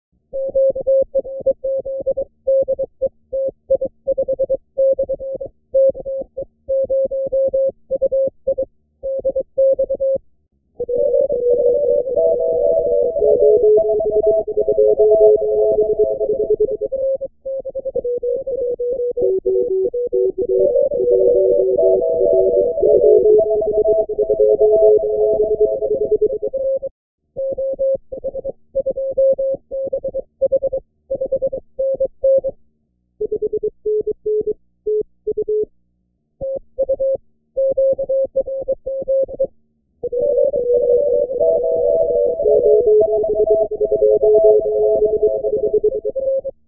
Trip to New Contest Station